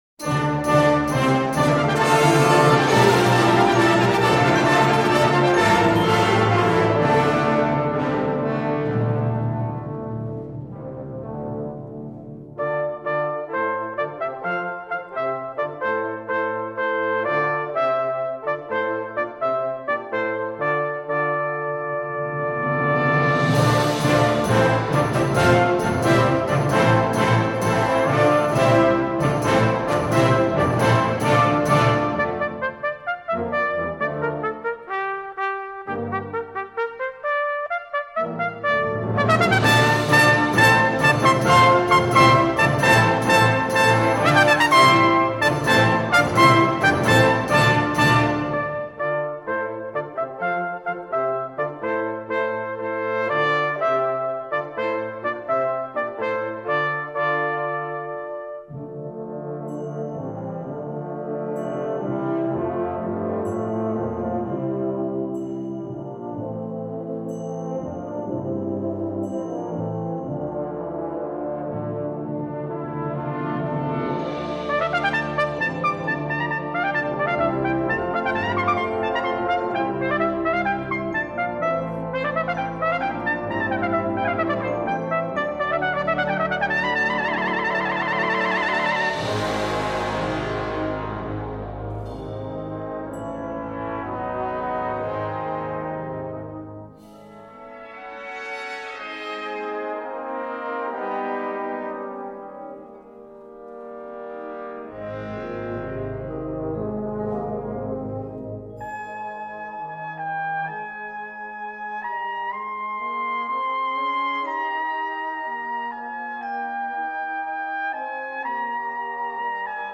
Brass Band
Solo & Brass Band